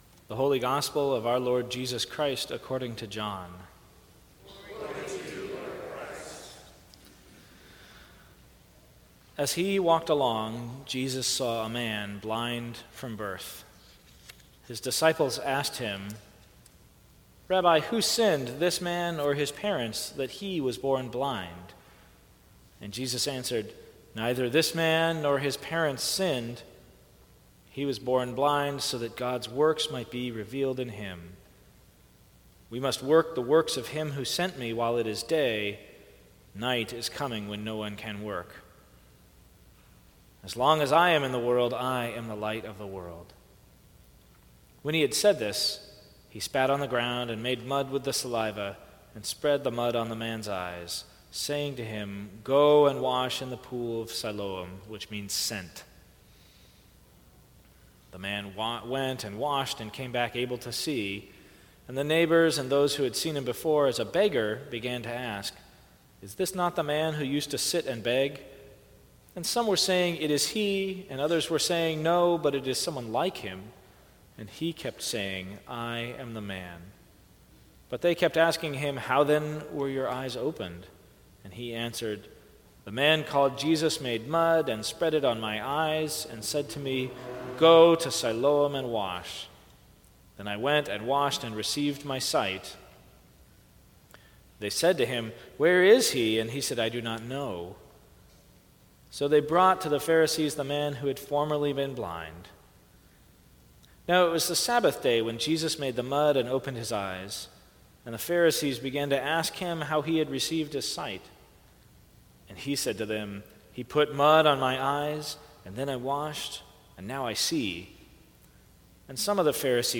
Sermons from St. Cross Episcopal Church Fourth Sunday in Lent Mar 26 2017 | 00:20:46 1x 00:00 / 00:20:46 Subscribe Share Apple Podcasts Spotify Overcast RSS Feed Share Link Embed